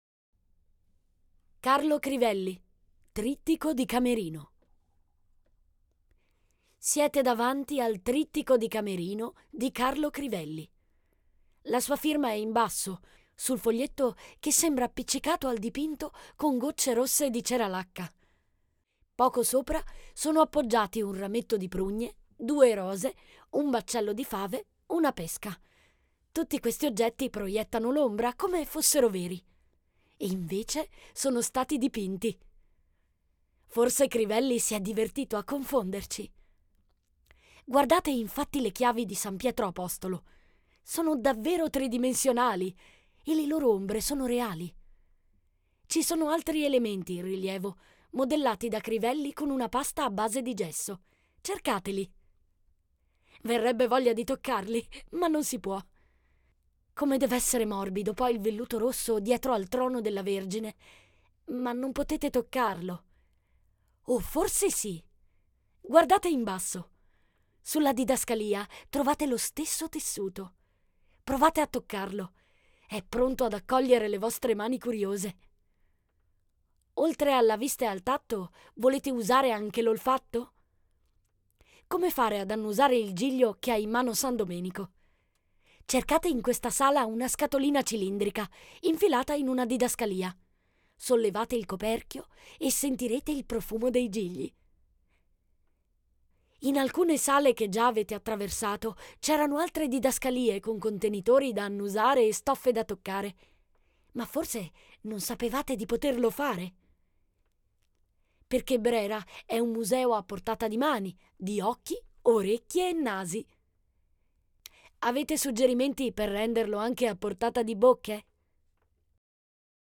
AI Audio
Audioguida – 30 minuti con… Carlo Crivelli